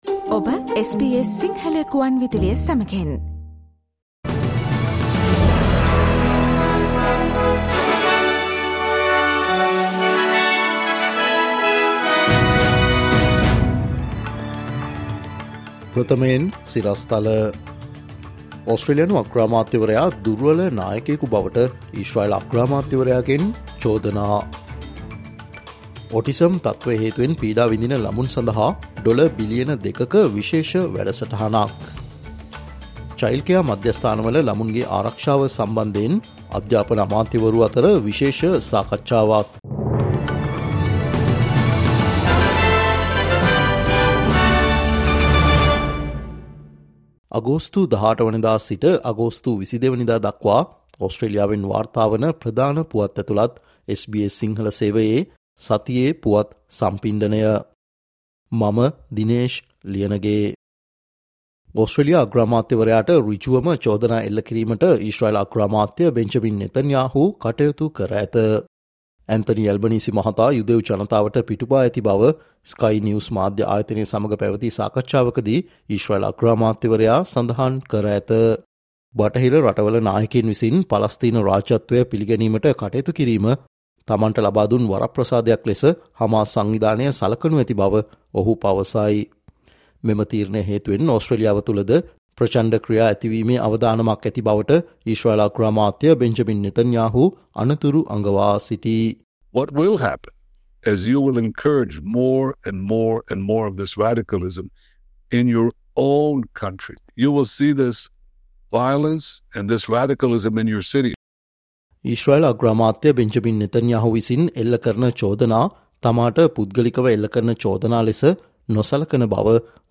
අගෝස්තු 18 සිට අගෝස්තු 22 වනදා දක්වා වන මේ සතියේ ඕස්ට්‍රේලියාවෙන් වාර්තාවන පුවත් ඇතුලත් සතියේ පුවත් ප්‍රකාශයට සවන් දෙන්න